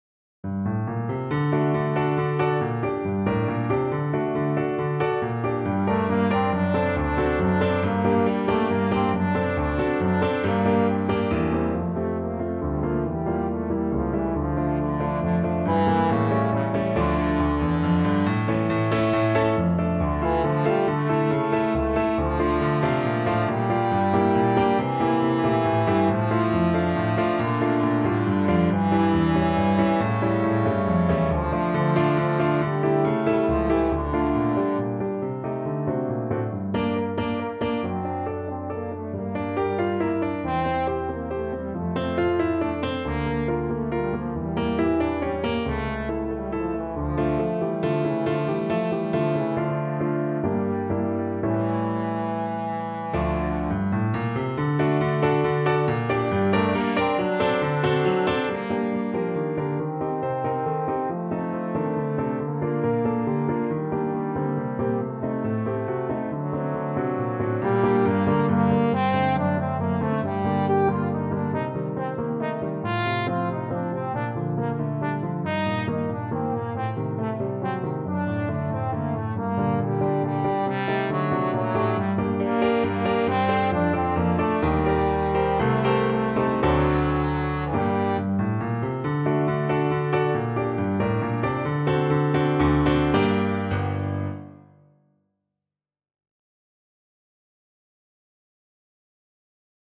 Allegro con spirito =138 (View more music marked Allegro)
3/4 (View more 3/4 Music)
Classical (View more Classical Trombone Music)